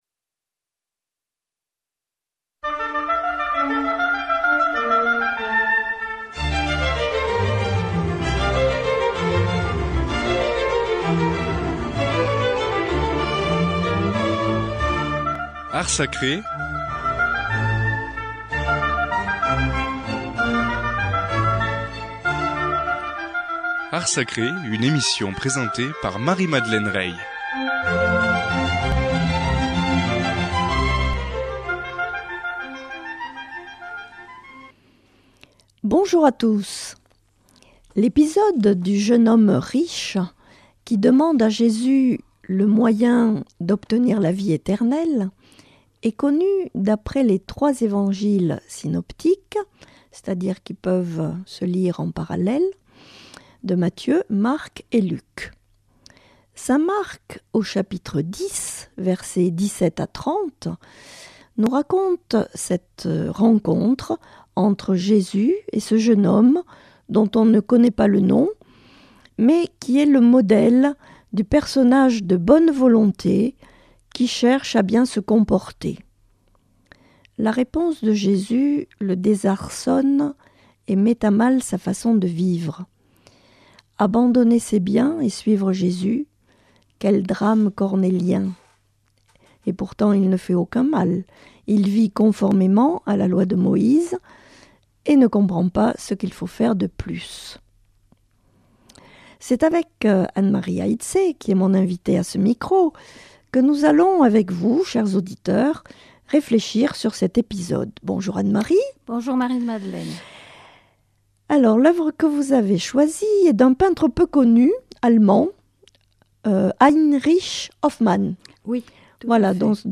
[ Rediffusion ] Bonjour à tous !